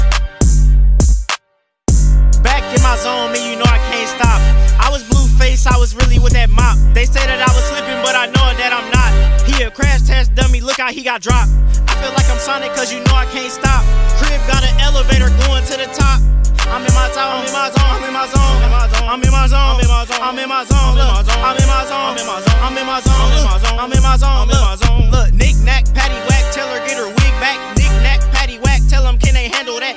Hip-Hop Rap Pop